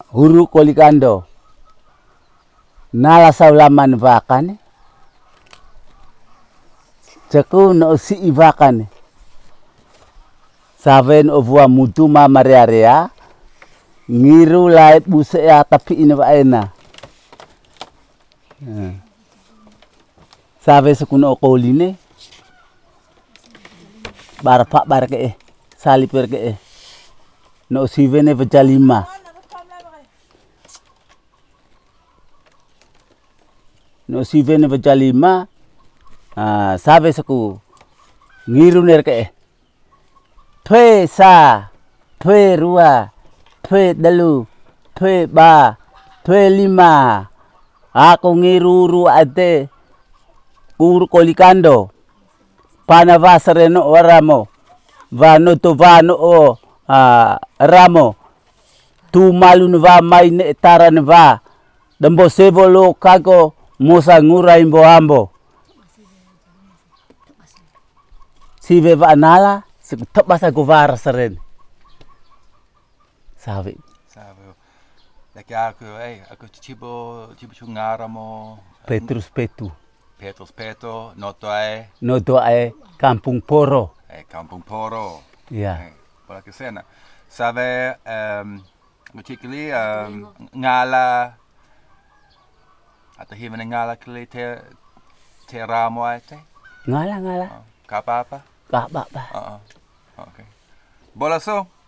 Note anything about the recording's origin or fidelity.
Palu'e, Flores, Nusa Tenggara Timur, Indonesia. Recording made in kampong Poro, Ndeo domain.